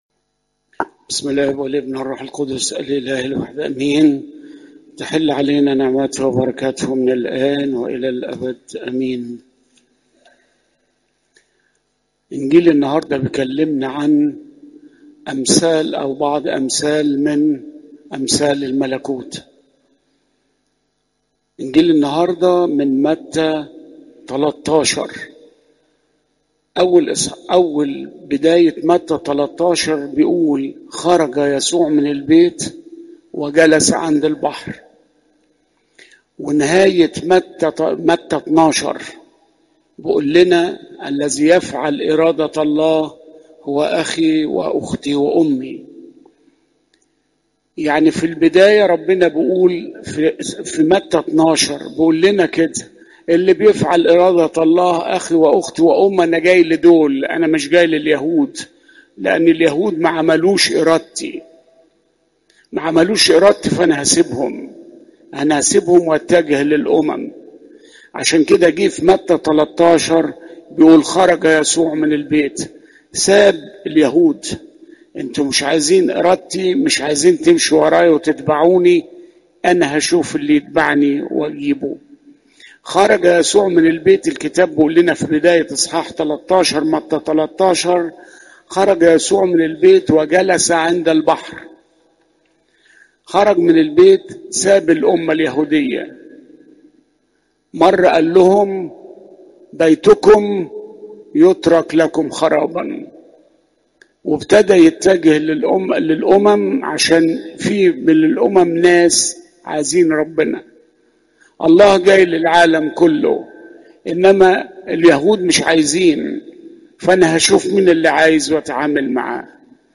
عظات قداسات الكنيسة (مت 13 : 24 - 43)